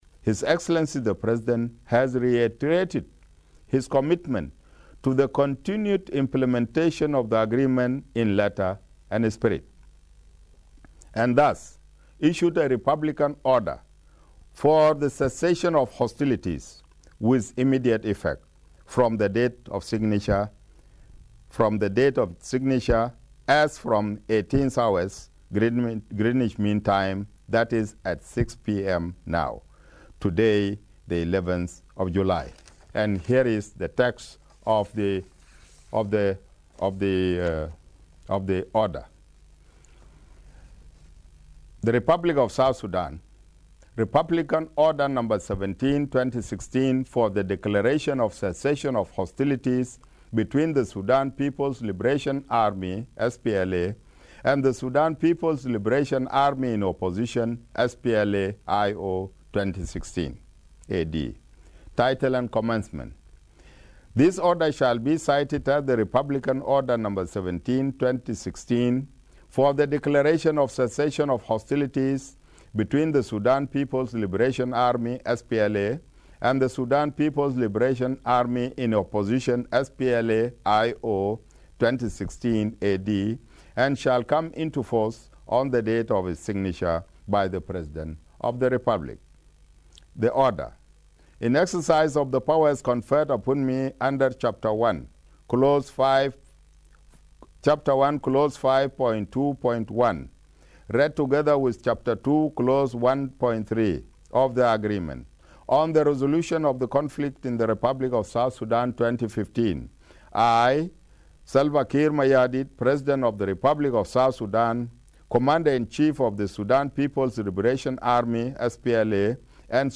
Government Spokesperson, Micheal Makuei read this statement on National Television